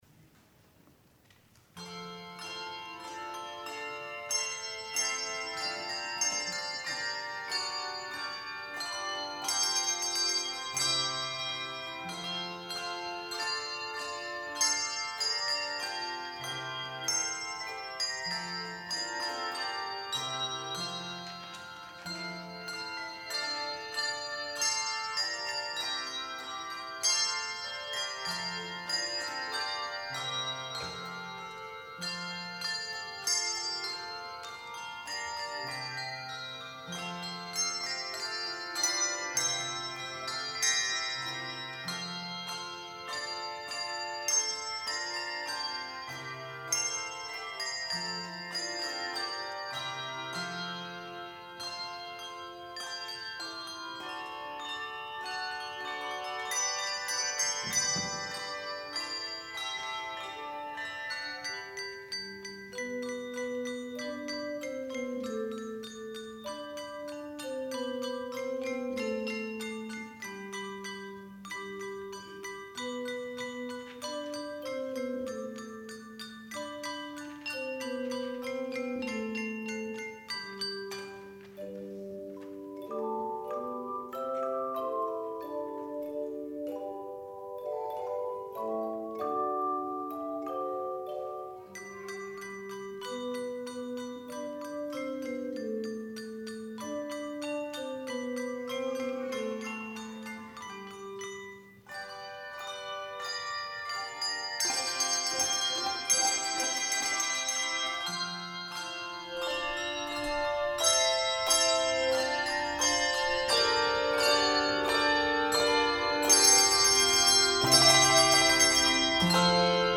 Performer:  Handbell Choir